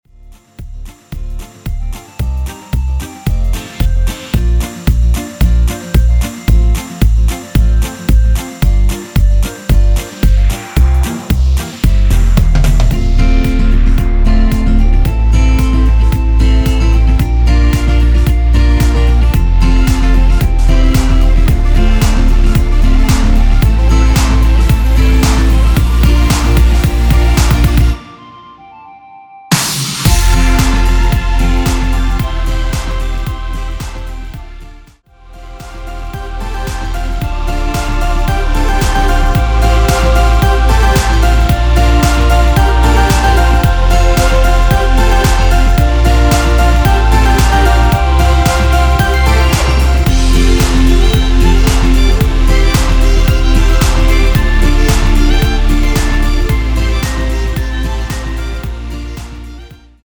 원키에서(+5)올린 멜로디 포함된 MR입니다.
앞부분30초, 뒷부분30초씩 편집해서 올려 드리고 있습니다.
중간에 음이 끈어지고 다시 나오는 이유는